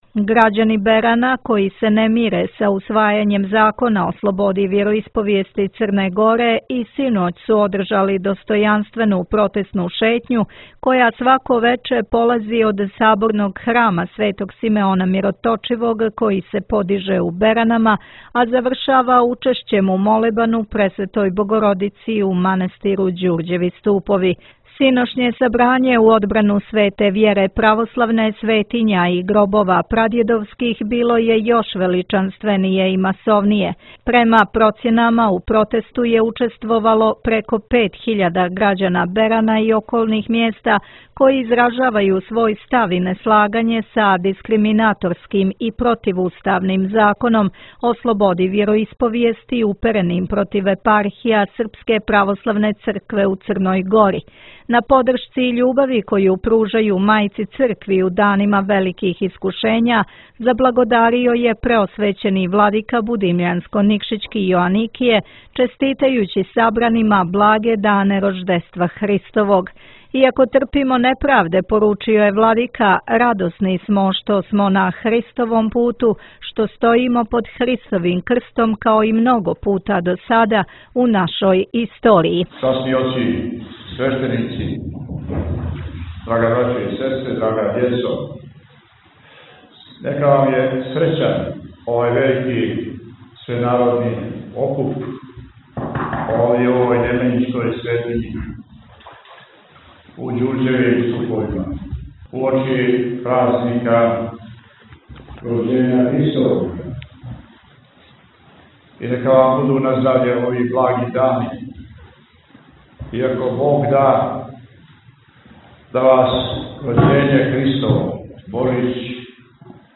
Грађани Берана, који се не мире са усвајањем Закона о слободи вјероисповјести Црне Горе, и у понедјељак 30. децембра 2019, одржали су достојанствену протестну шетњу, која, свако вече, полази од Саборног храма Светог Симеона Мироточивог, који се подиже у Беранама, а завршава учешћем у Молебану Пресветој Богородици, у манастиру Ђурђеви Ступови.
На подршци и љубави коју пружају Мајци Цркви, у данима великих искушења, заблагодарио је Преосвећени Епископ Јоаникије, честитајући сабранима благе дане Рождества Христовог.